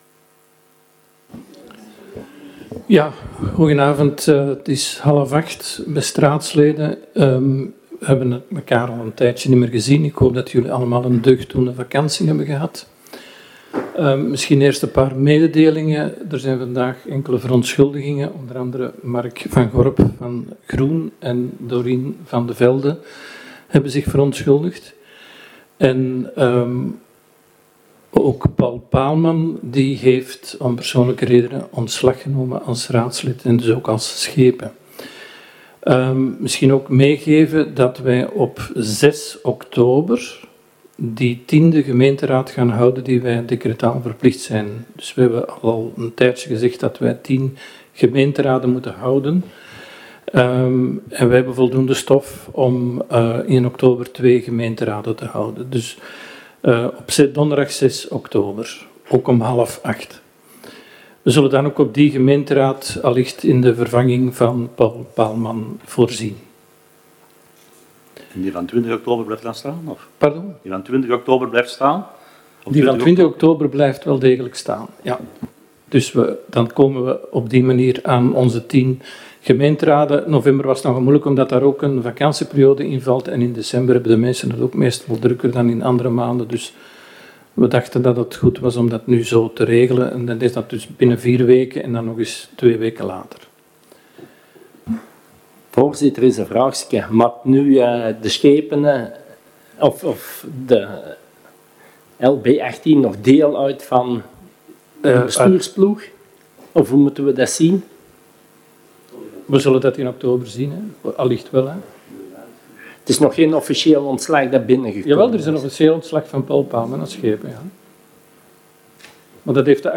Gemeentehuis